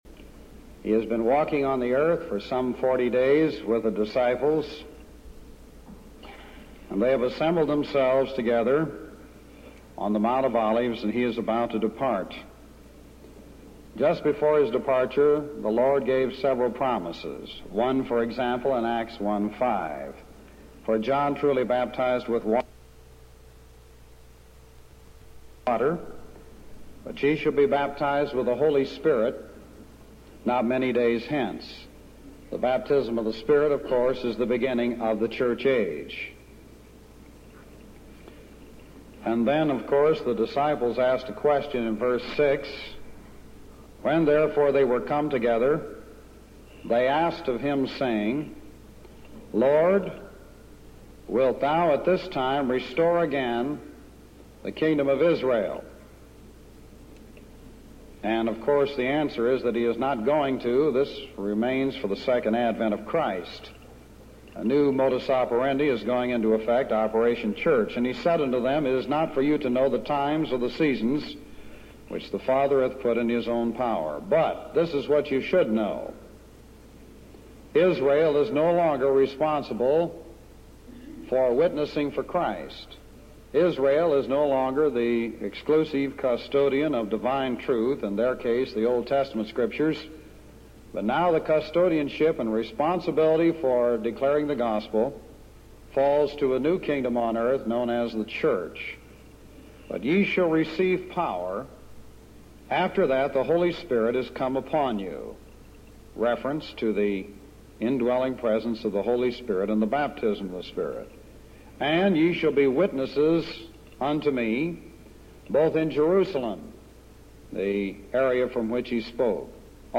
Bible Study on soulwinning.mp3